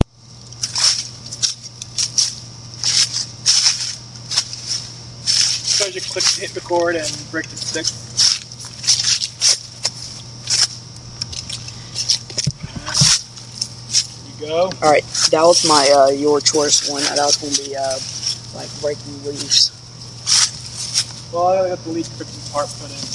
叶子
描述：有人走过树叶的声音
Tag: 自然 生活 外界